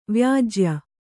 ♪ vyājya